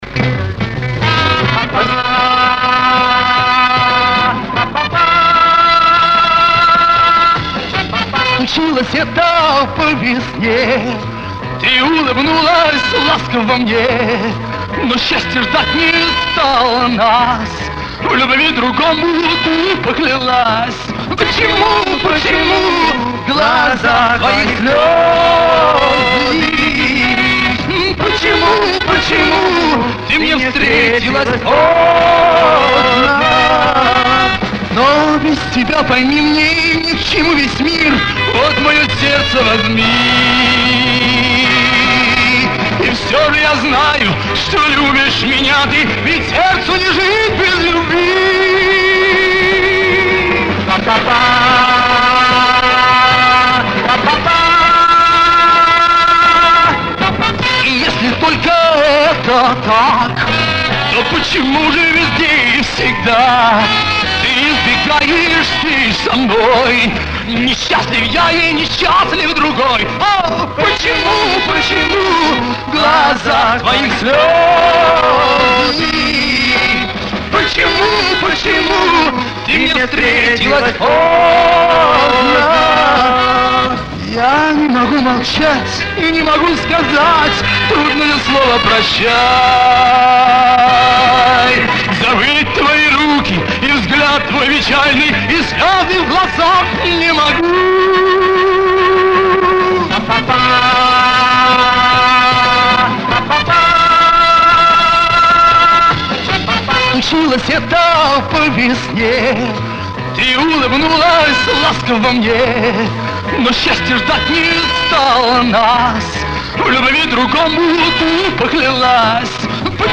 бэк вокал
К сожалению исходник очень плохого качества
эл. орган